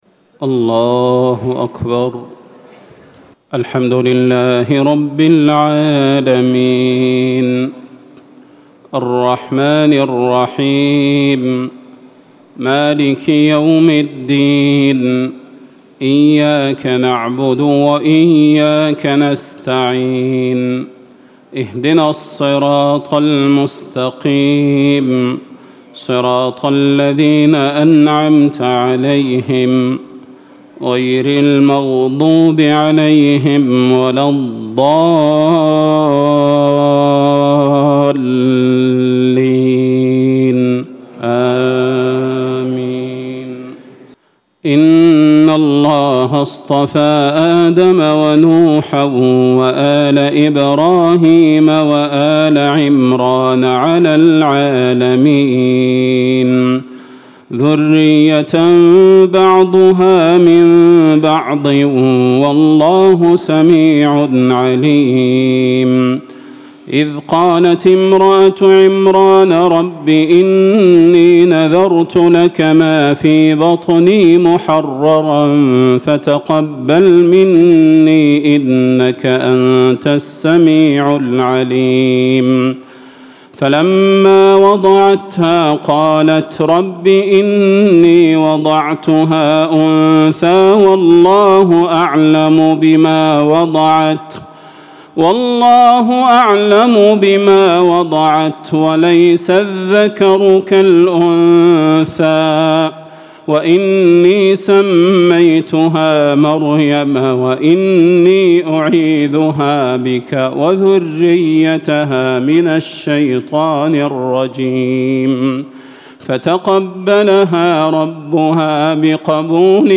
تهجد ليلة 23 رمضان 1437هـ من سورة آل عمران (33-92) Tahajjud 23 st night Ramadan 1437H from Surah Aal-i-Imraan > تراويح الحرم النبوي عام 1437 🕌 > التراويح - تلاوات الحرمين